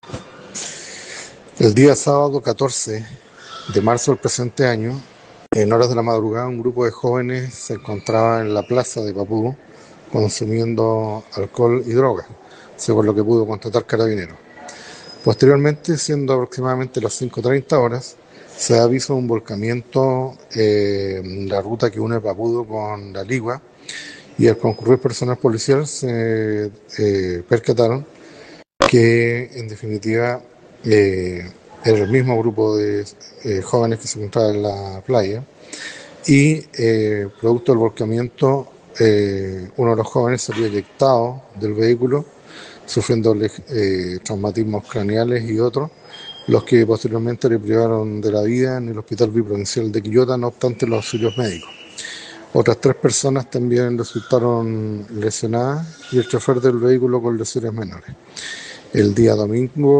El fiscal de La Ligua, Sergio Moya, detalló cómo fueron las horas previas al accidente: “Un grupo de jóvenes se encontraba en la plaza de Papudo consumiendo alcohol y drogas, según lo que pudo constatar Carabineros (…) A las 5:30 se avisa un volcamiento en la ruta que une Papudo con La Ligua, y al concurrir personal policial, se percataron que, en definitiva, era el mismo grupo de jóvenes que se encontraba (anteriormente) en la playa“.
Fiscal-Sergio-Moya.mp3